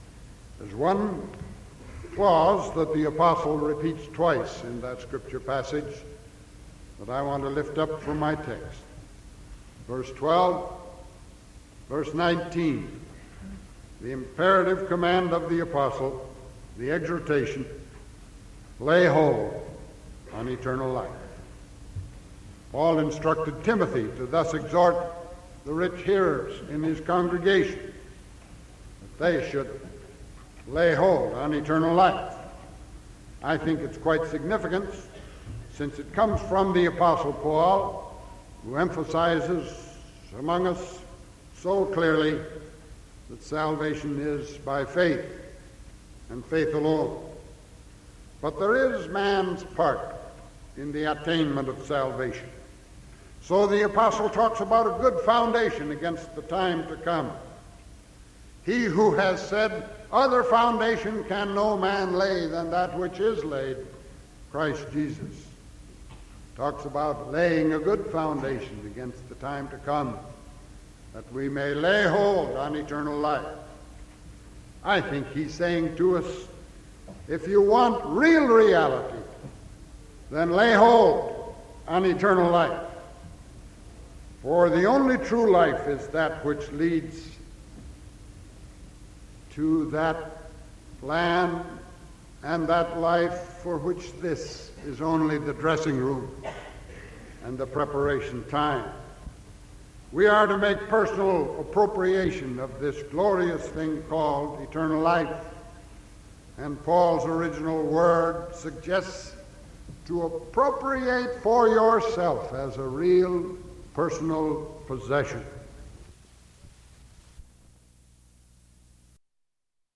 Sermon from November 3rd 1974 PM